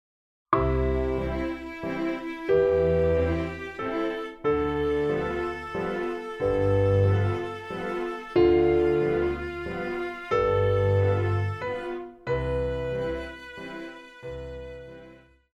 Classical
Orchestra
Solo with accompaniment